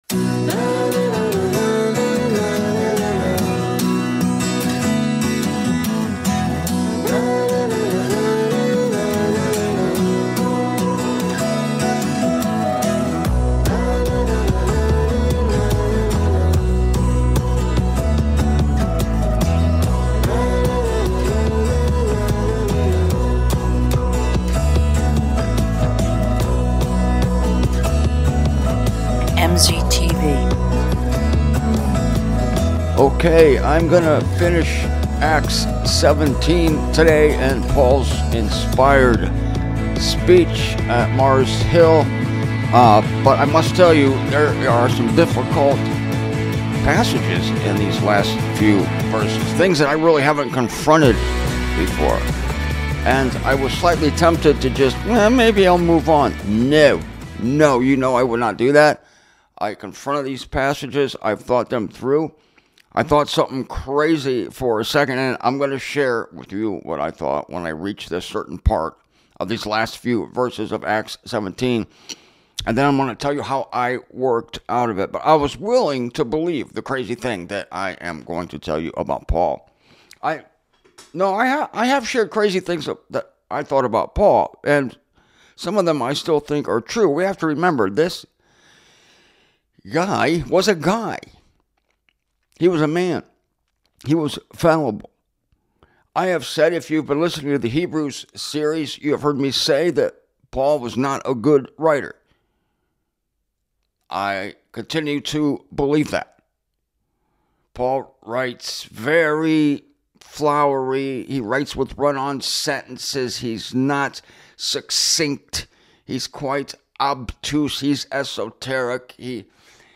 I really like the conversational tone of this presentation.